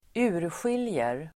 Uttal: [²'u:rsjil:jer]